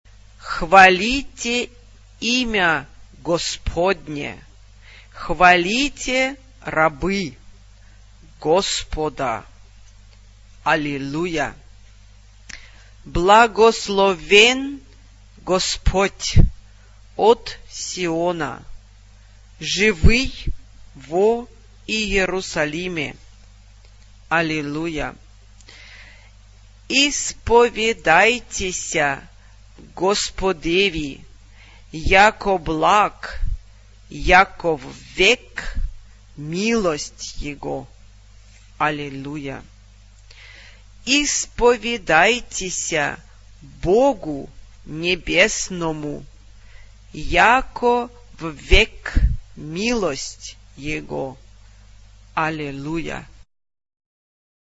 Genre-Style-Form: Sacred ; Orthodox liturgical hymn
Mood of the piece: calm ; slow ; lilting ; firm
Type of Choir: SSSAATTTBBB  (11 mixed voices )
Tonality: A flat major
sung by Chamber Choir of the Ministry of Culture of USSR conducted by Valeri Polyansky